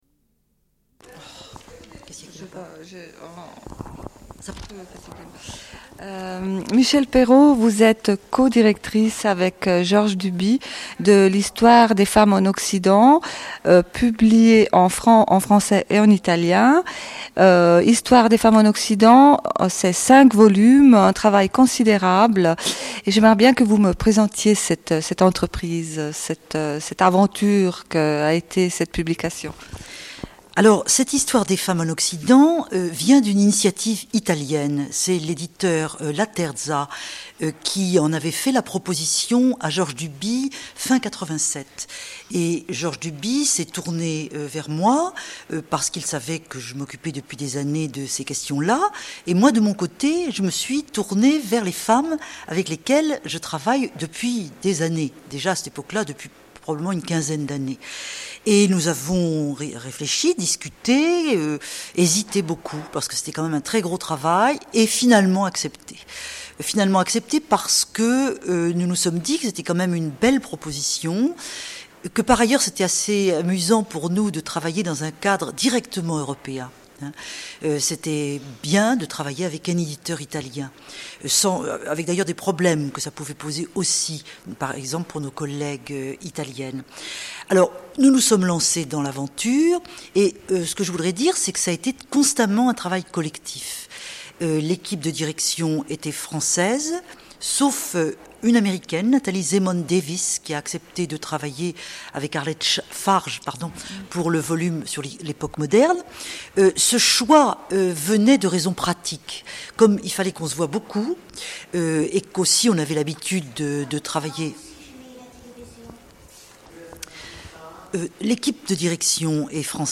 Interview de Michelle Perrot - Archives contestataires
Une cassette audio, face A20:55
Entretien avec Michelle Perrot, autrice de Histoire des femmes en Occident en cinq tomes, avec Georges Duby.